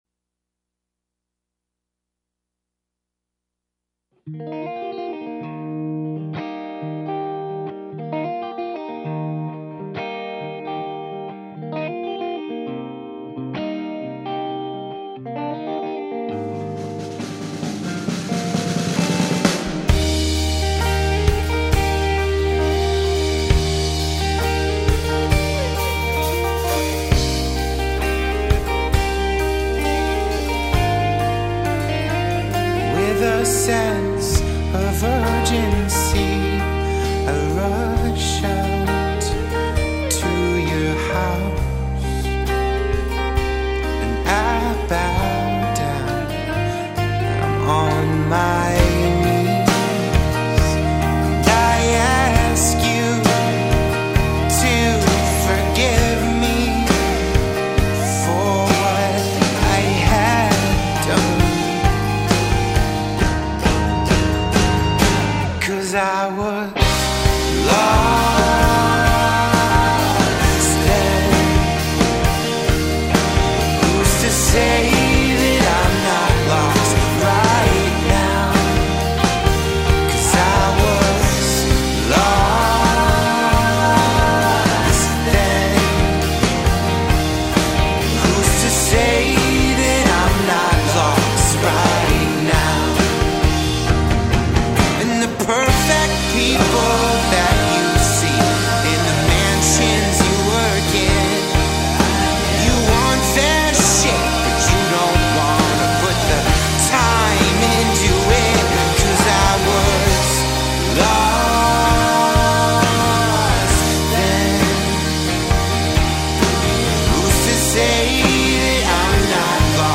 the six-piece having recently released their debut 5 song EP